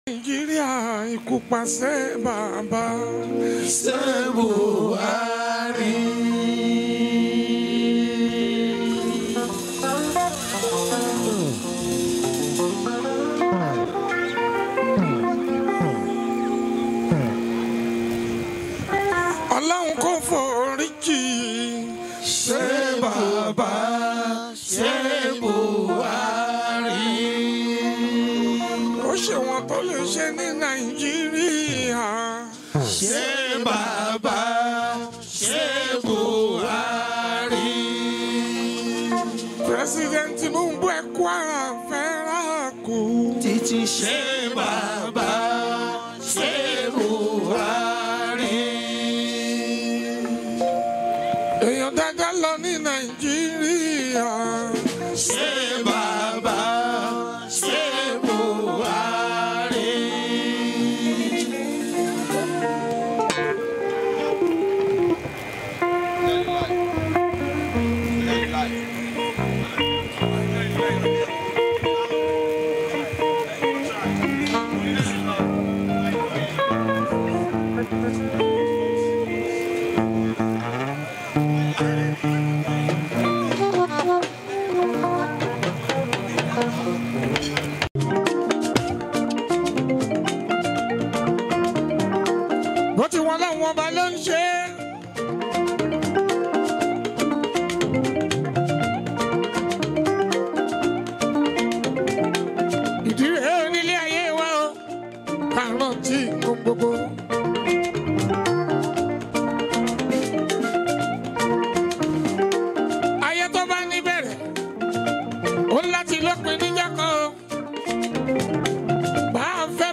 Nigerian Yoruba Fuji track